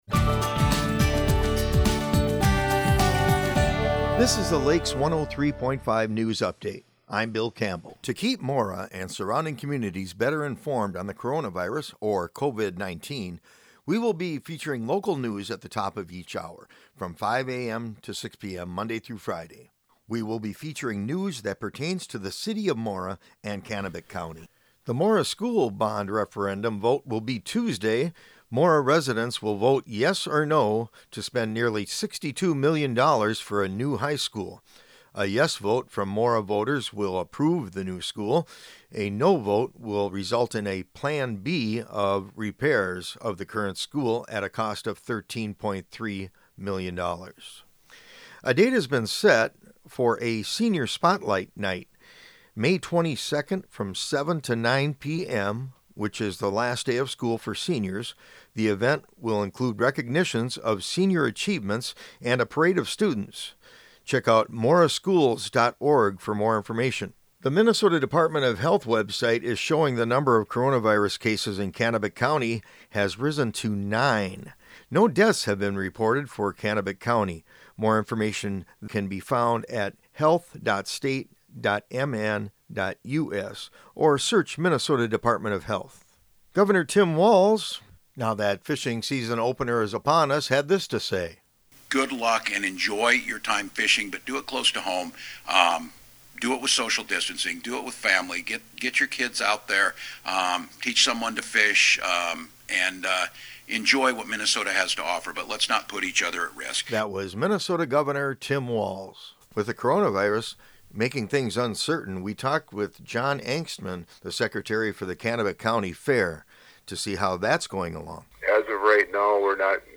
This is an archived recording of a feature originally broadcast on Lakes 103.